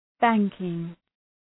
Shkrimi fonetik {‘bæŋkıŋ}
banking.mp3